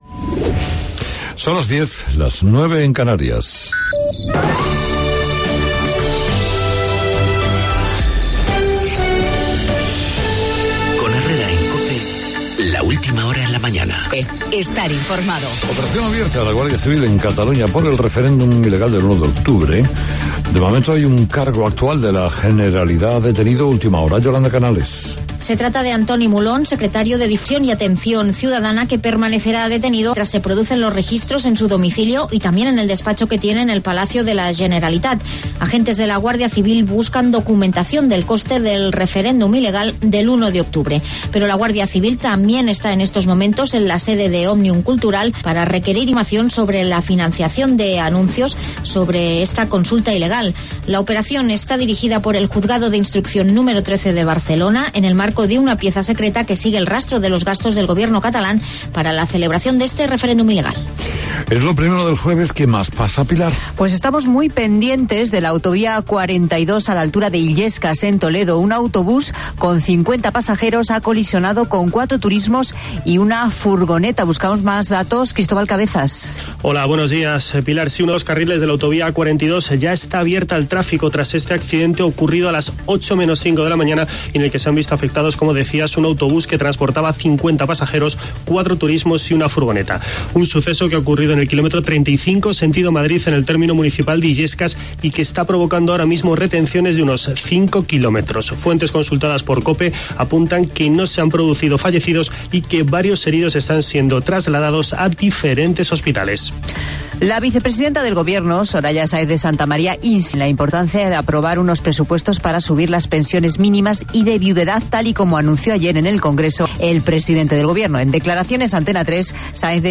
Boletín informativo de las 10.00 horas de la Cadena COPE